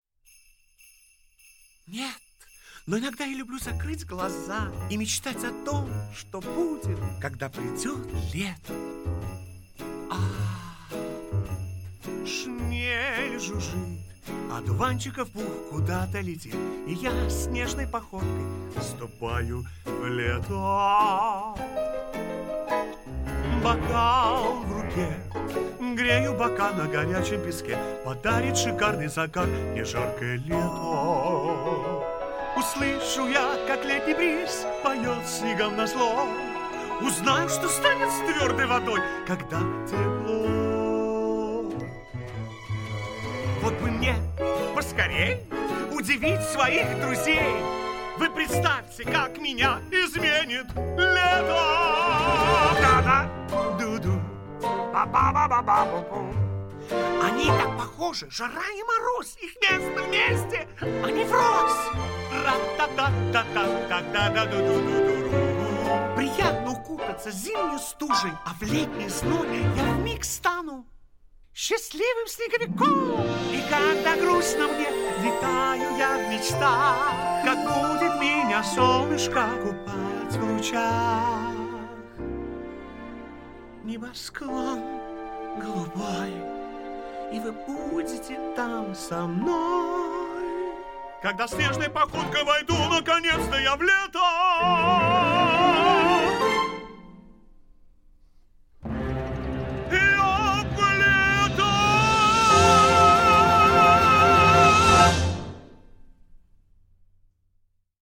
• Категория: Детские песни
🎶 Детские песни / Песни из мультфильмов